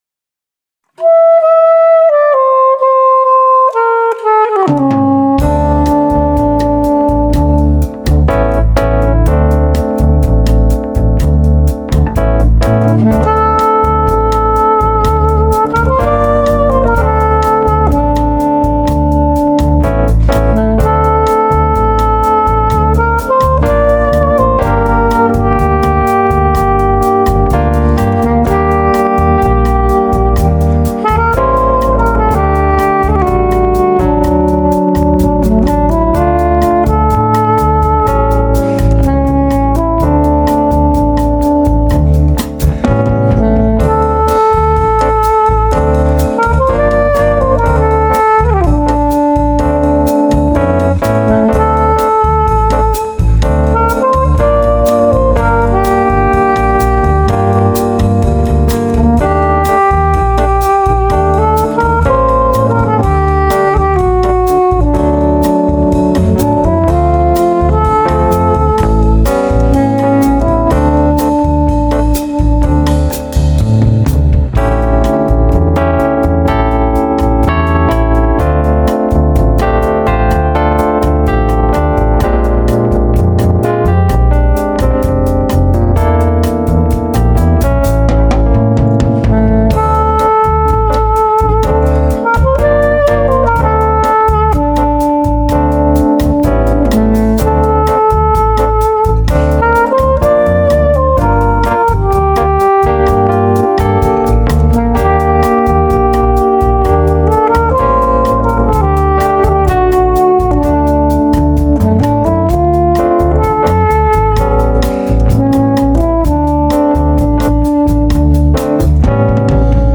im Studio (03/19)